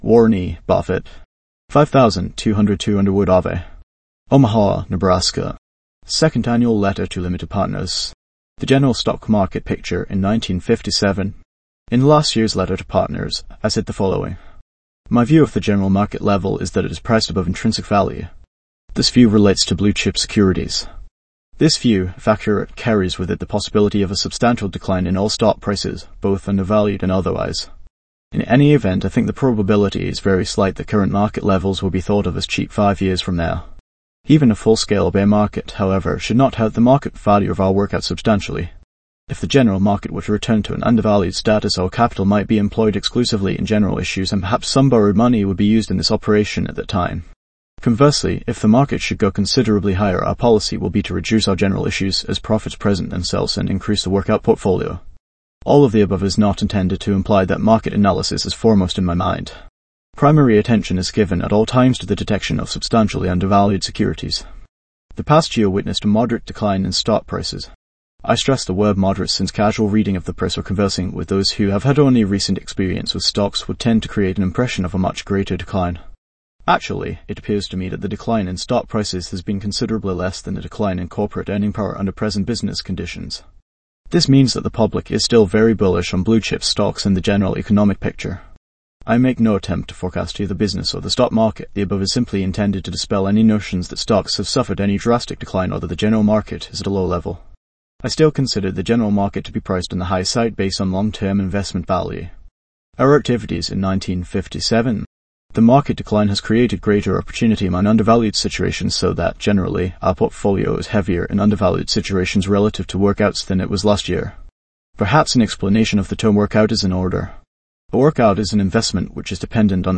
value-investors-tts - a voice model for